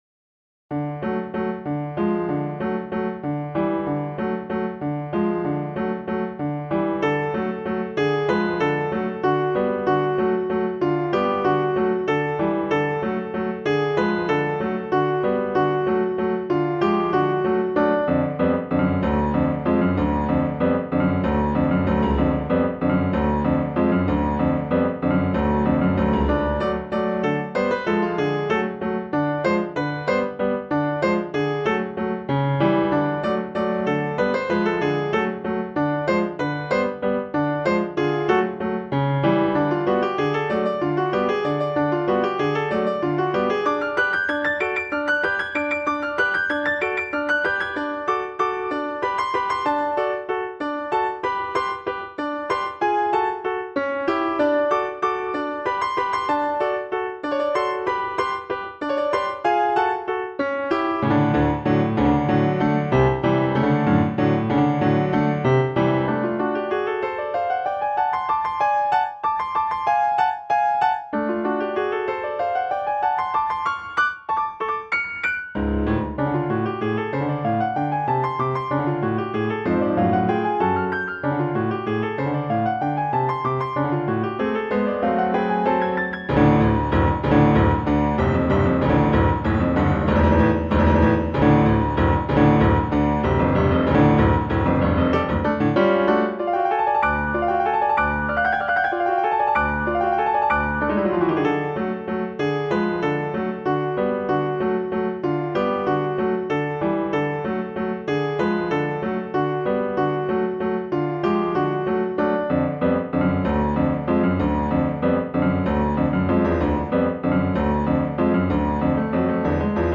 Two movement piano piece in D Major
Please note that some of the grace notes are written like they are due to a better playback performance.